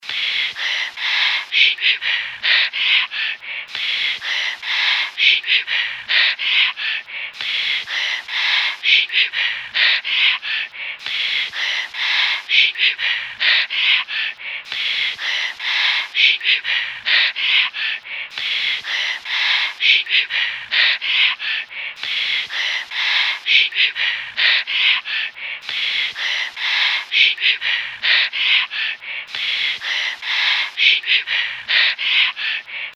I recorded some audio of an AM all-news radio station, and went through it to pick out the sounds of the announcers taking a breath before speaking.
This is a 3-second piece which took 3 hours to create, here repeated 10 times over for a 32-second passage of random radio announcers’ breathing.
It came out even weirder than I imagined.
inhale_002_x10.mp3